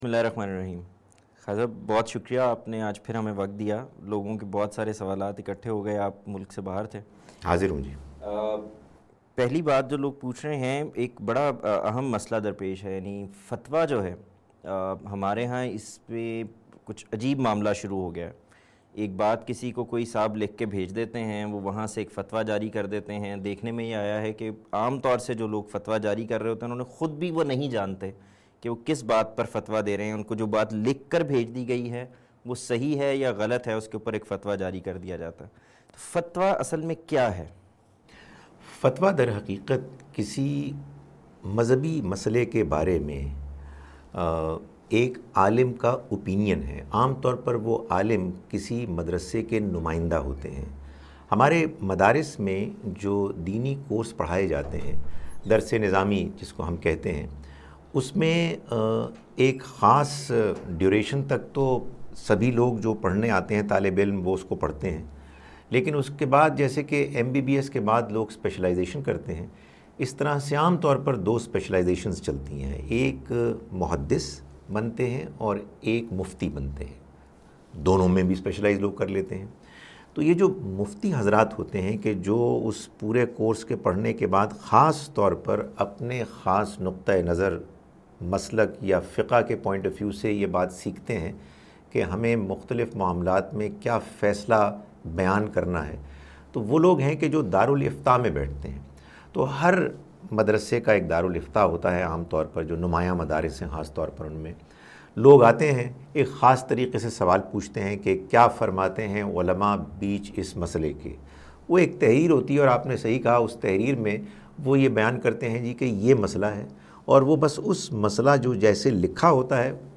Program "Ask A Question" where people ask questions and different scholars answer their questions